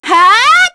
Artemia-Vox_Skill4_kr.wav